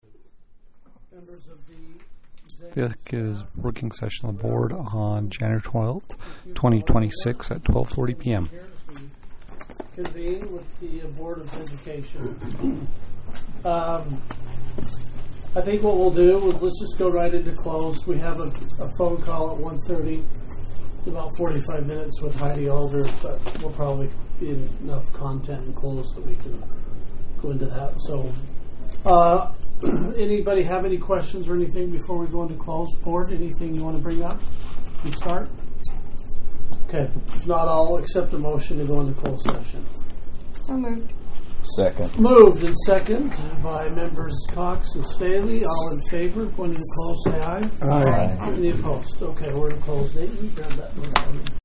Meeting